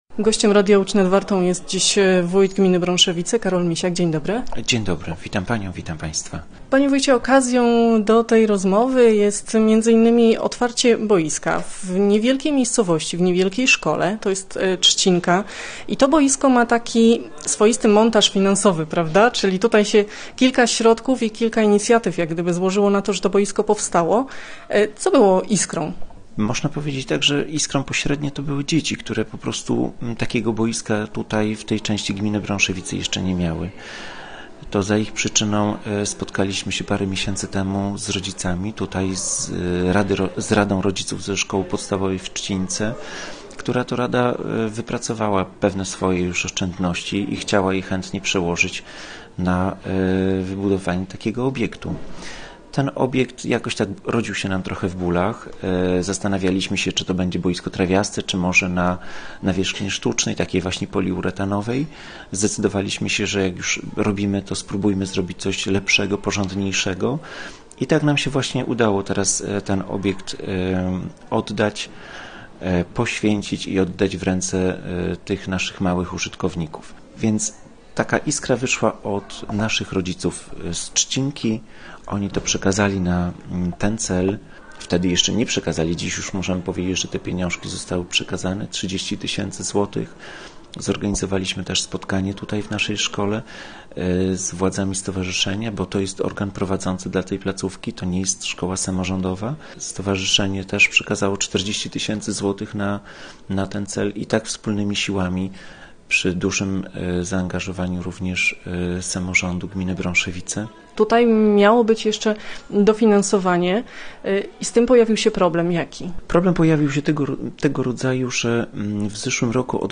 Gościem Radia Łódź Nad Wartą był wójt gminy Brąszewice, Karol Misiak.